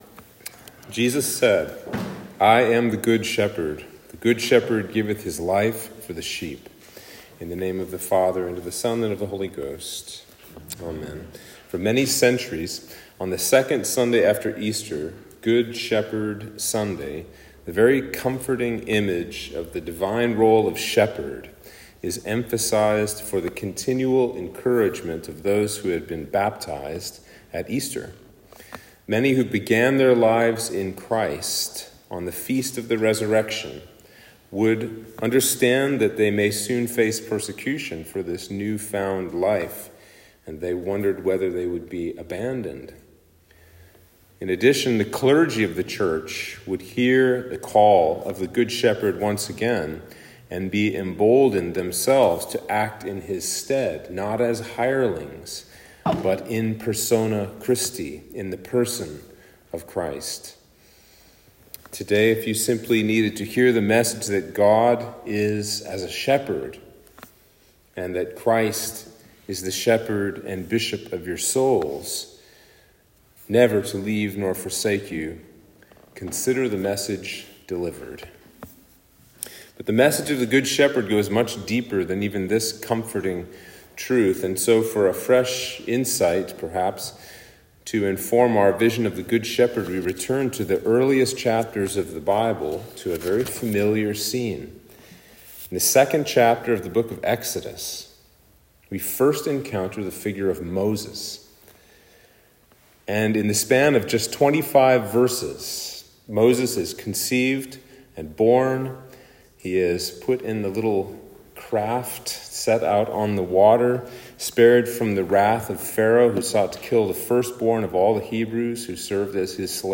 Sermon for Easter 2